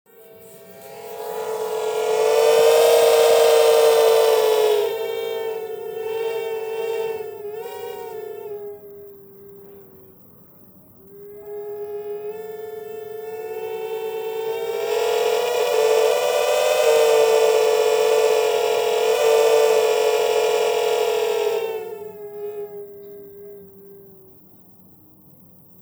listen to the noise (<click here-opens in new tab) we suffer every time we have windy weather and gales.